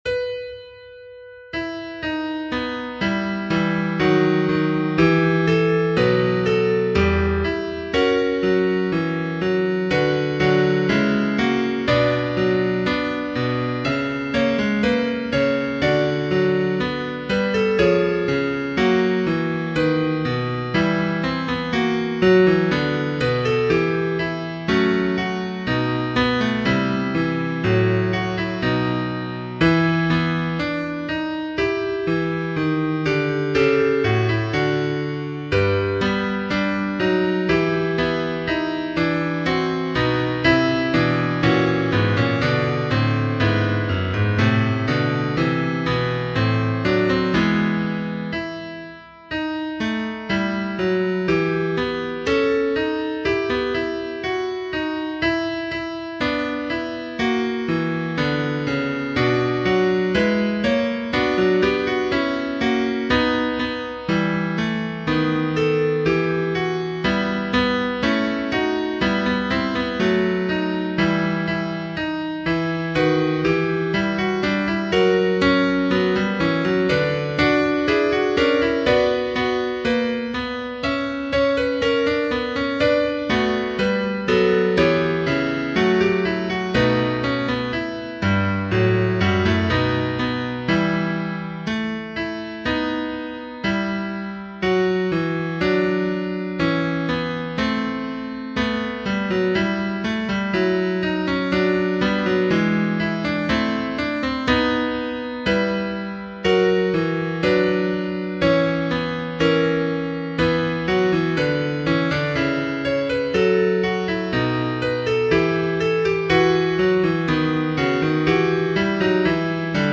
Tutti (mp3)
Au tempo 60